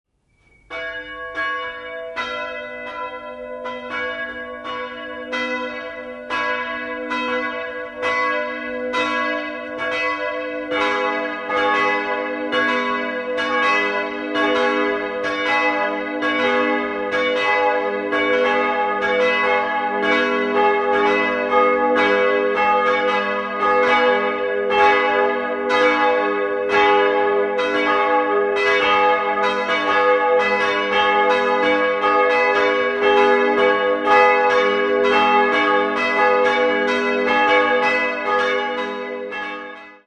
Jahrhundert verändert wurde, besitzt eine für diese Gegend typische barocke Ausstattung. 3-stimmiges TeDeum-Geläute: gis'-h'-cis'' Die beiden größeren Glocken (gis'+7 und h'+9) wurden 1952 von Rudolf Perner in Passau gegossen.